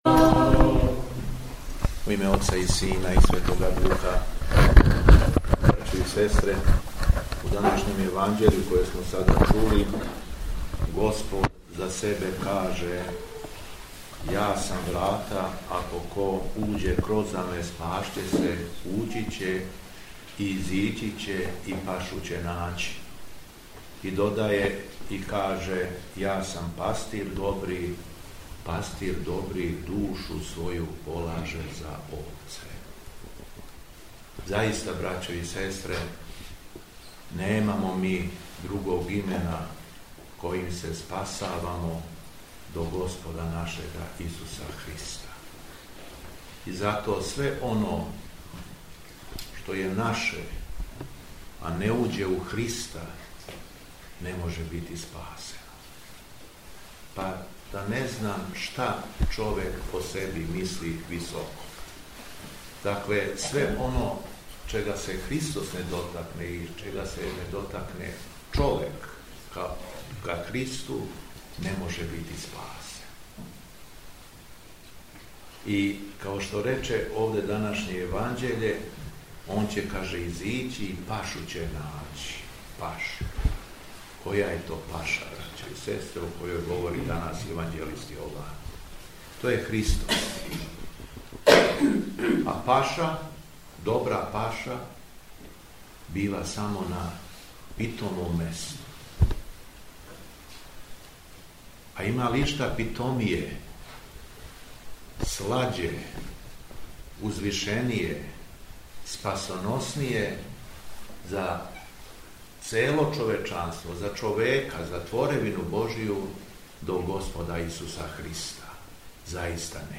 Беседа Његовог Високопреосвештенства Митрополита шумадијског г. Јована
Након прочитаног зачала из Светог Јеванђеља, Високопреосвећени Митрополит обратио се верном народу речима:
Дана 27. фебруара 2025. године, у четвртак Сиропусне седмице, када наша Света Црква прославља Светог Кирила Словенског и Преподобног Авксентија, Архиепископ крагујевачки и Митрополит шумадијски Г. Јован служио је Свету Архијерејску Литургију у манастиру Ралетинац.